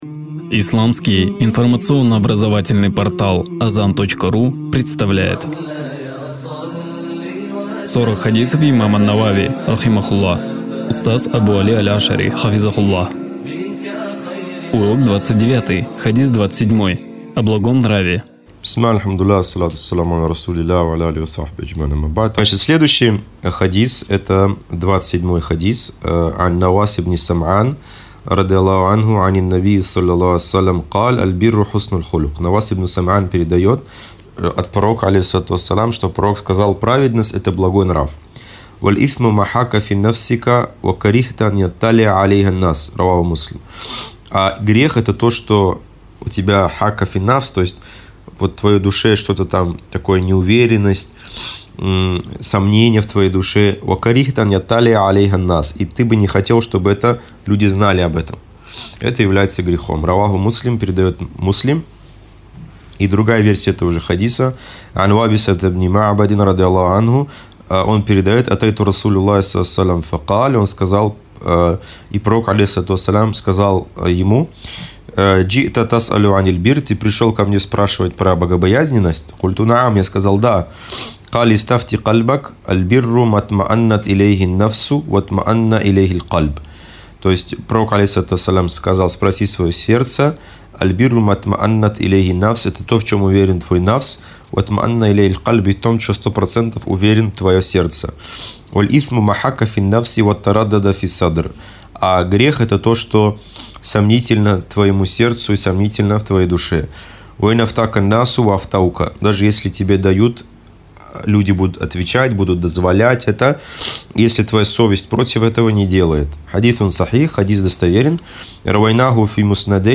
Мы рады представить вам новый цикл уроков по книге выдающегося учёного Ислама, Имама Мухйиддина ан-Навави, рахимахуллах, «Сорок хадисов».